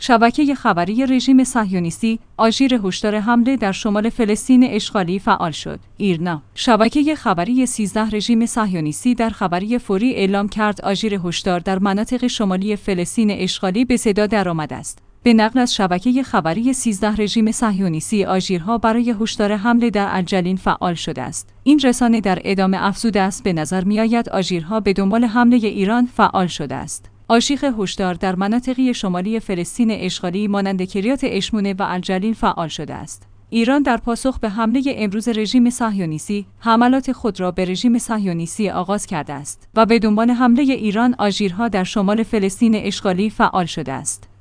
آژٍیر هشدار